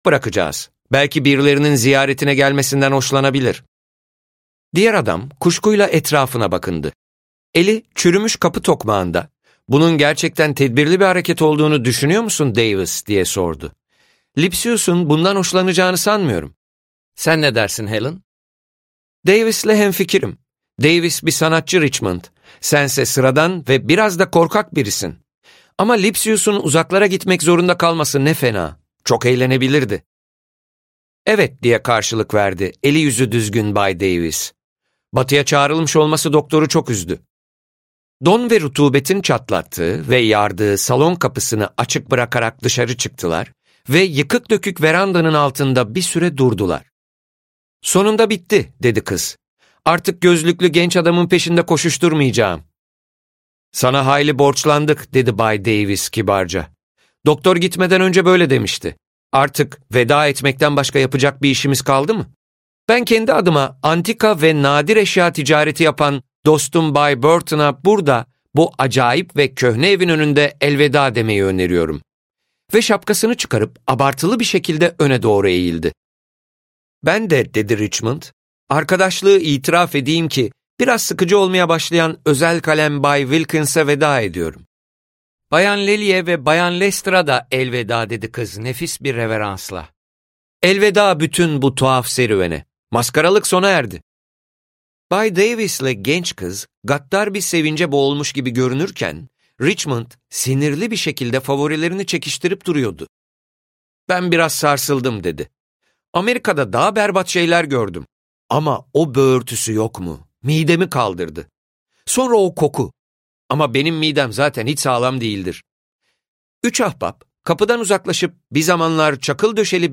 Üç Sahtekar - Seslenen Kitap